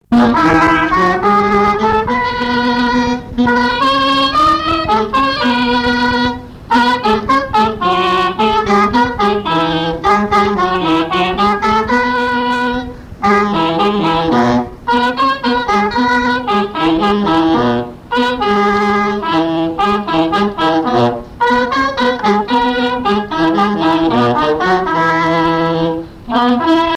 trompette
saxophone
Basse
clarinette
circonstance : fiançaille, noce
Ensemble de marches de noces